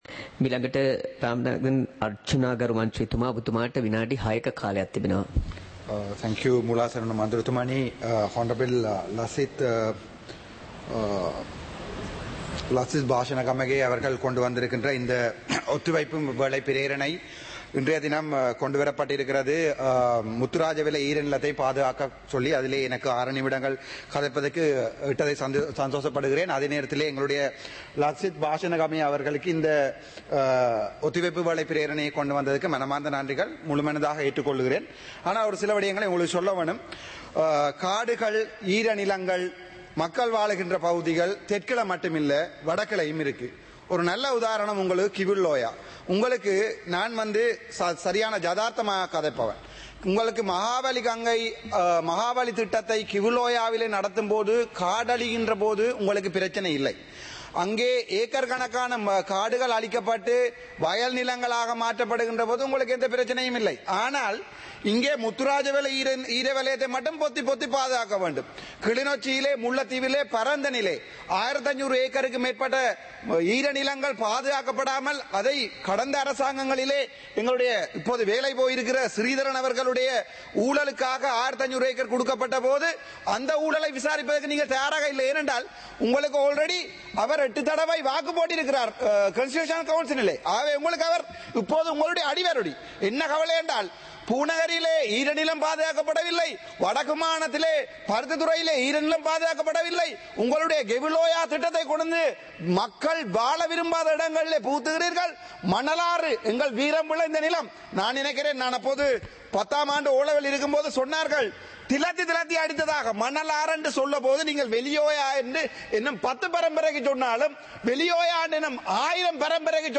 Parliament of Sri Lanka - Proceedings of the House (2026-02-03)
Parliament Live - Recorded